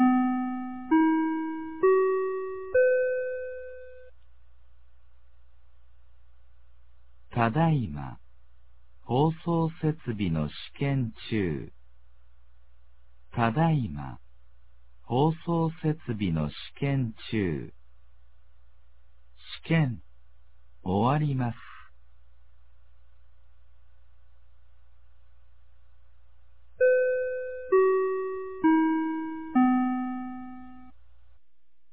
2026年02月21日 16時06分に、美浜町より全地区へ放送がありました。